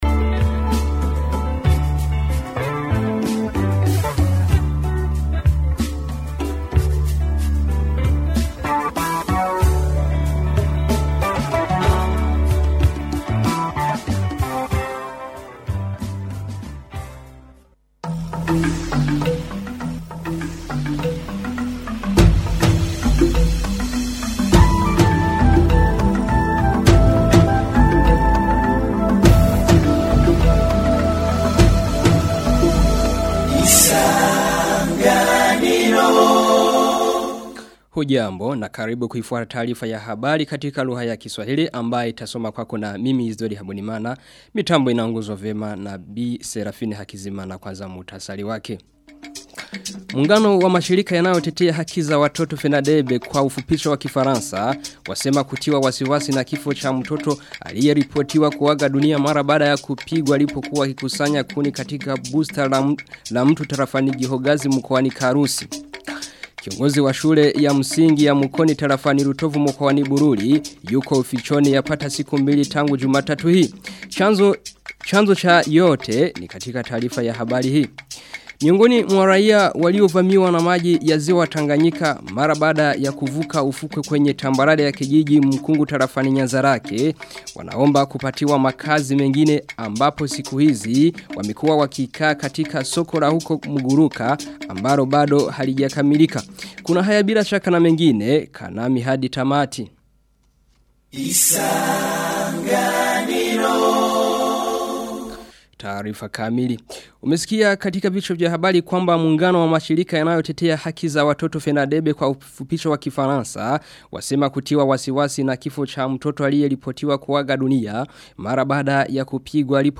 Taarifa ya habari tarehe 15 april 2020